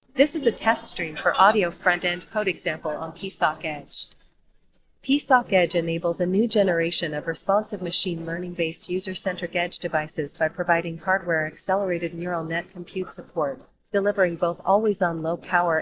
同じ音源を「処理前」と「AIによるノイズ除去後」で比較しています。
ノイズ除去前の音声（音声＋ノイズ）